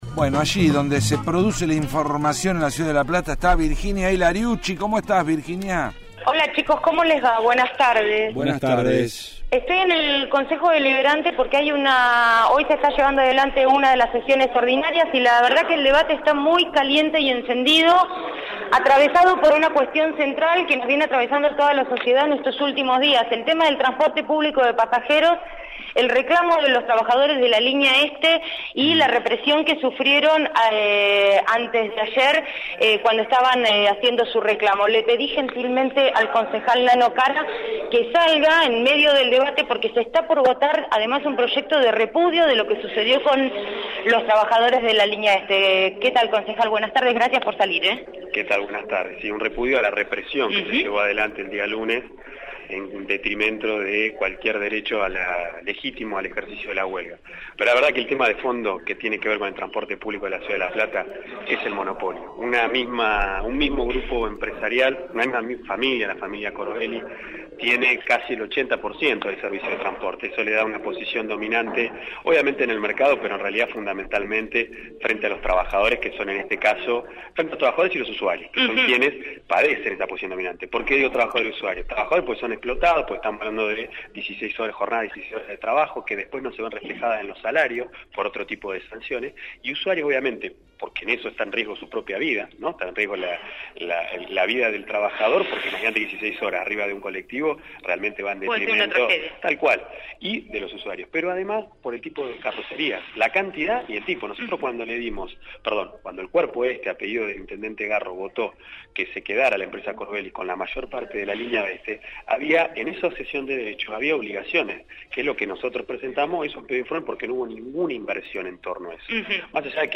desde el Concejo Deliberante platense